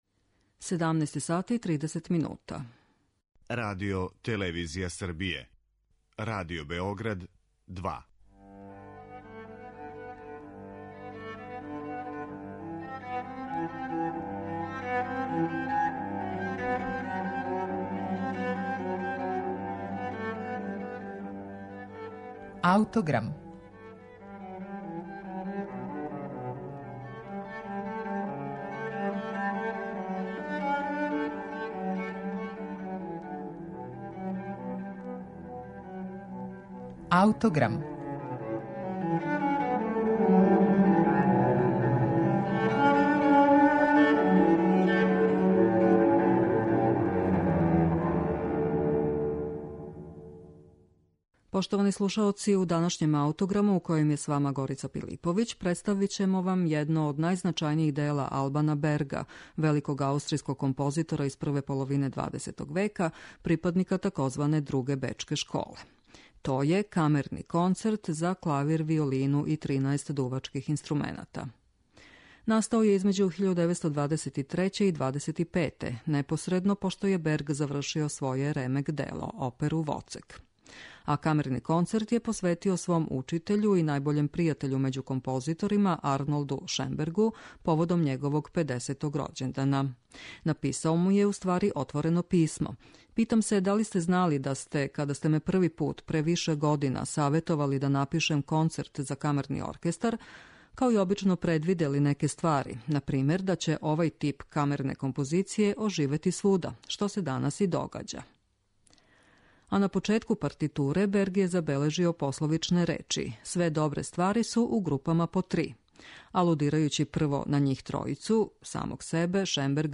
Камерни концерт за клавир, виолину и 13 дувачких инструмената, Албана Берга